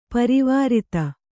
♪ parivārita